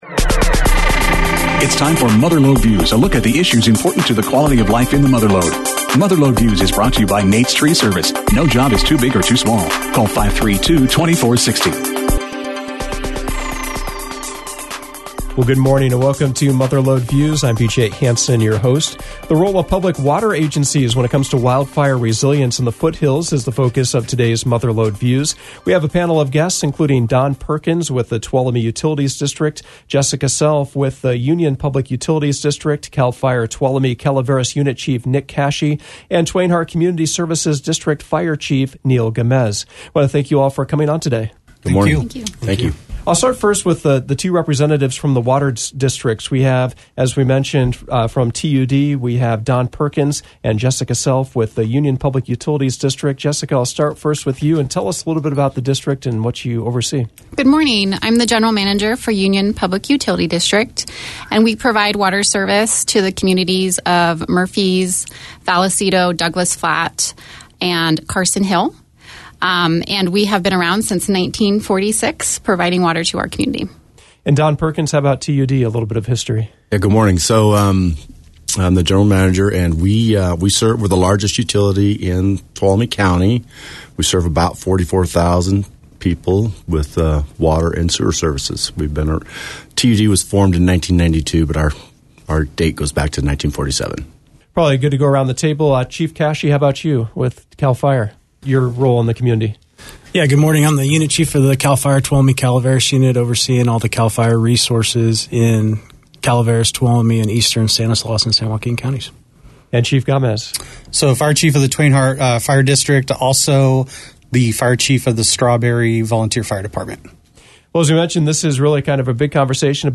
Mother Lode Views featured a panel discussion about the role water districts play during wildfires.